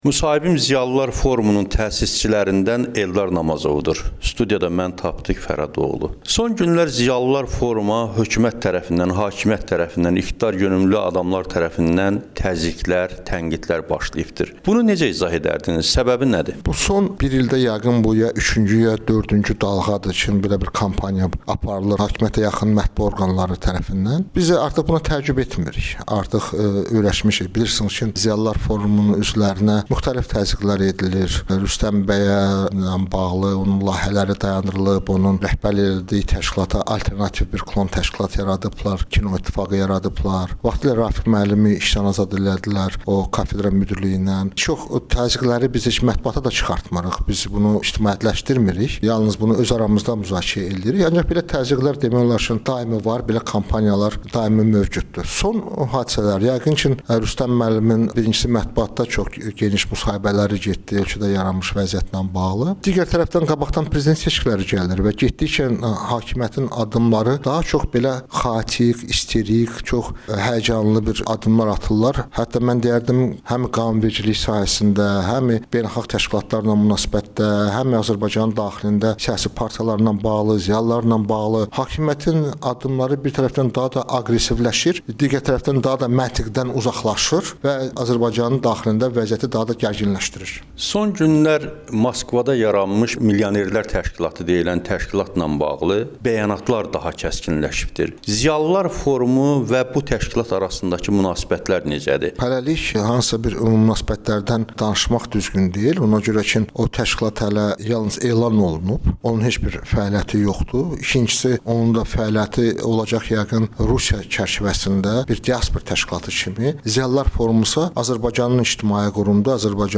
Eldar Namazovla müsahibə